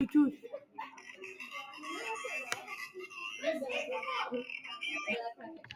voice record soundboard # meme